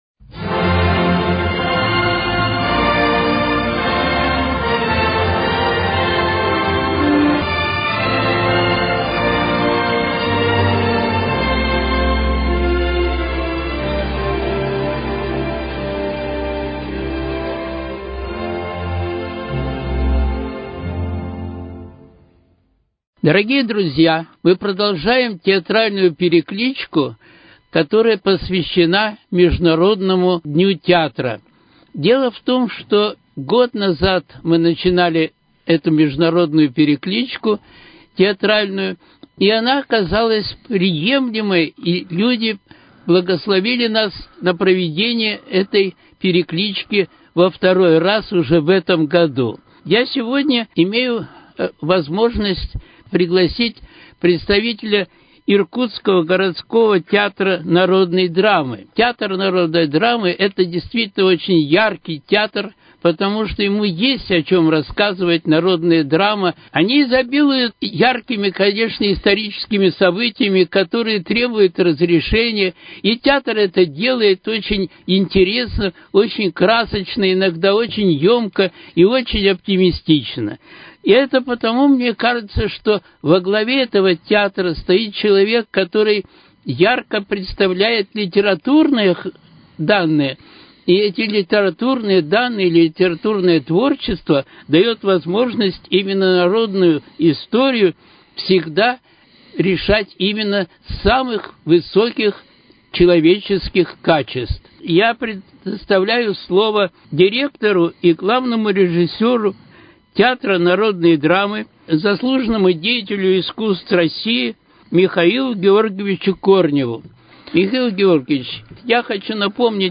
Театральная перекличка: Беседа